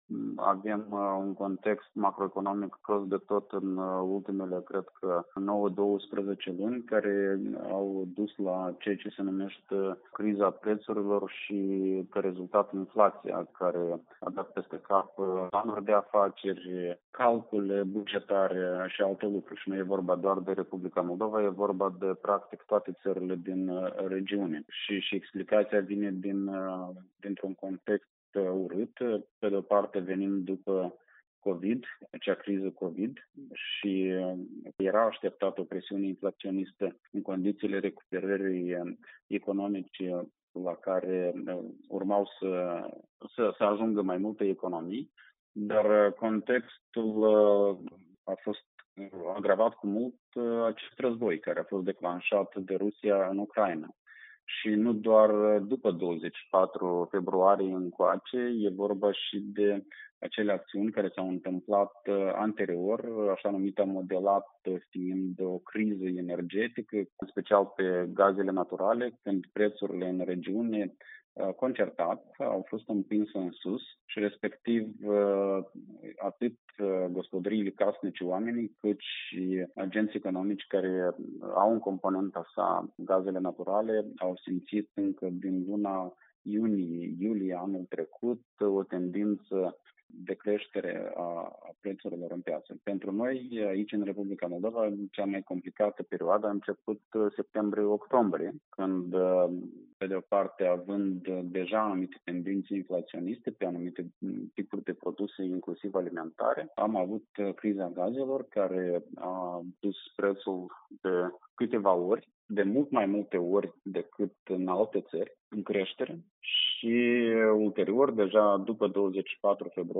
Consilierul prezidențial în domeniul economic, Veaceslav Negruță, a spus Europei Libere într-un interviu că prețurile alimentelor ar trebui să se reducă în perioada următoare, temperând tendințele inflaționiste.